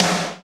Index of /90_sSampleCDs/Roland L-CDX-01/SNR_Snares 4/SNR_Sn Modules 4
SNR COMBO12R.wav